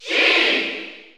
Category: Crowd cheers (SSBU) You cannot overwrite this file.
Sheik_Cheer_German_SSBU.ogg.mp3